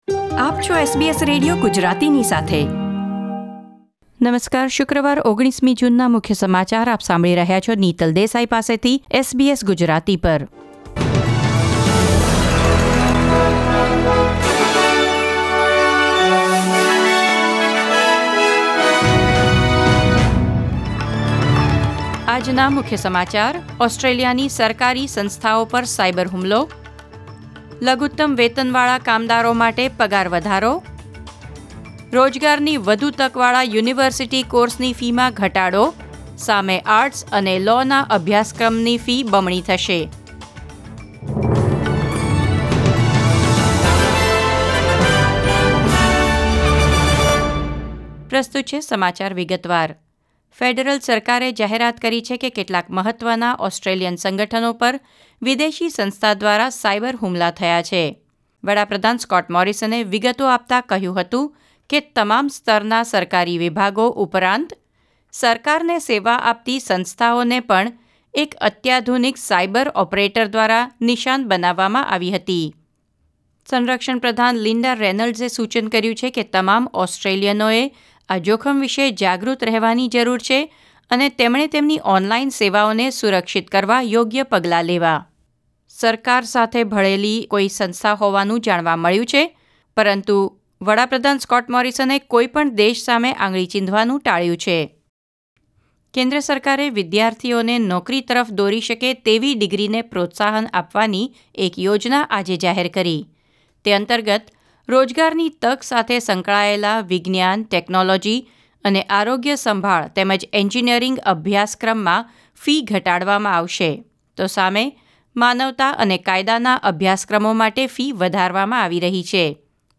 SBS Gujarati News Bulletin 19 June 2020